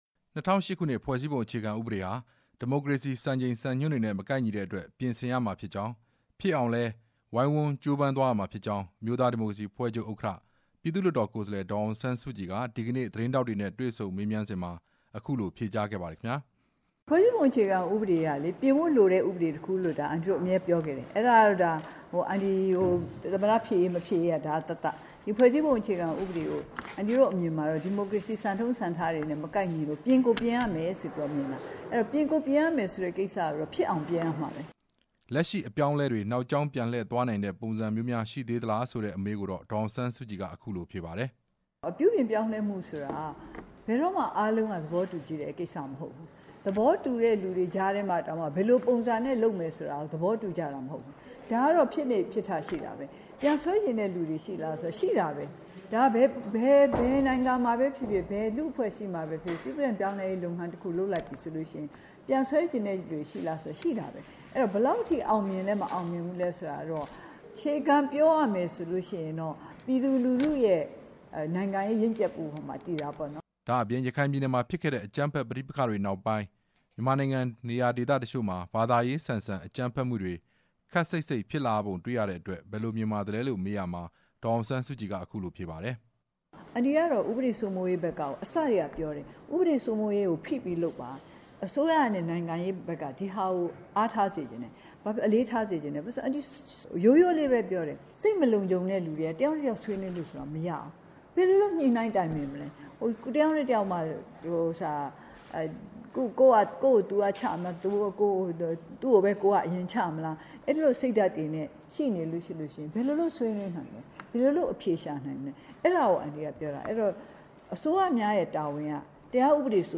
နေပြည်တော် ပြည်သူ့လွှတ်တော်တရား ဥပဒေ စိုးမိုးရေးနဲ့တည်ငြိမ်အေးချမ်းရေး ကော်မတီရုံးခန်းမှာ သတင်းမီဒီယာ သမားတွေနဲ့တွေ့ဆုံစဉ် သတင်းသမား တစ်ဦးရဲ့ မေးမြန်းချက်ကို ဒေါ်အောင်ဆန်းစုကြည်က  ဖြေကြားရင်း အခုလိုတိုက်တွန်းပြောဆိုခဲ့တာပါ။